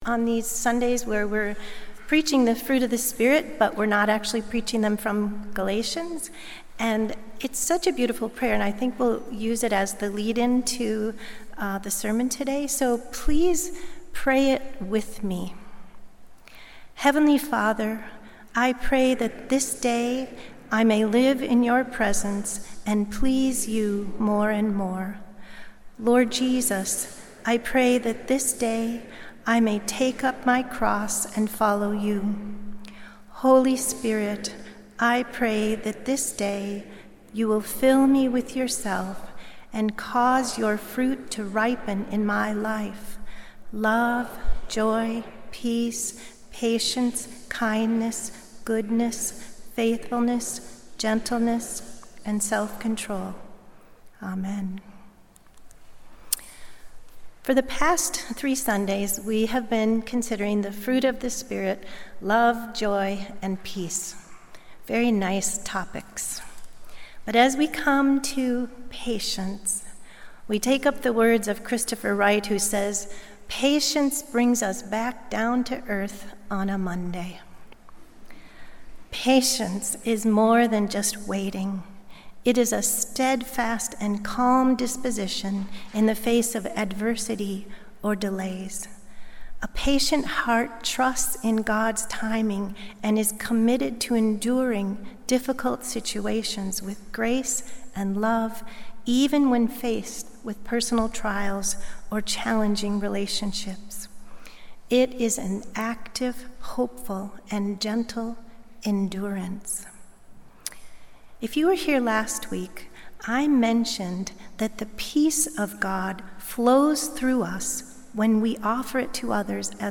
Sunday Worship–July 13, 2025